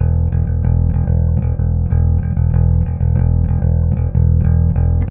Trem Trance Bass 02b.wav